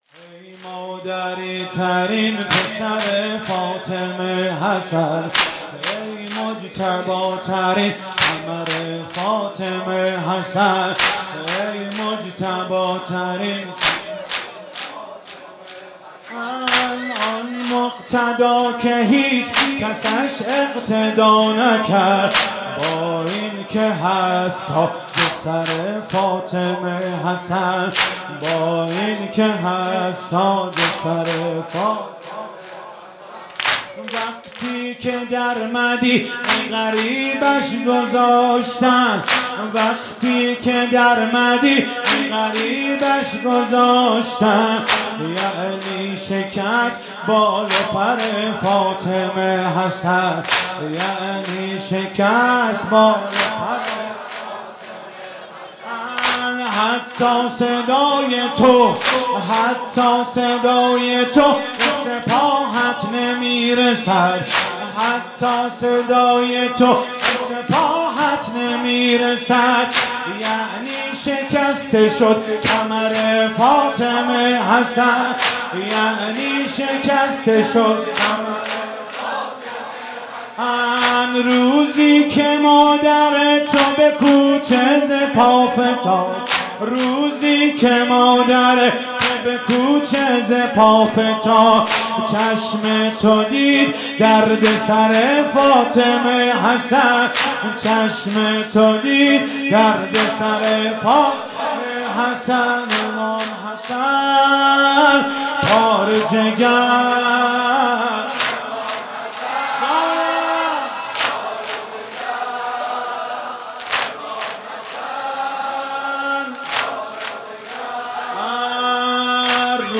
مداحی امام حسن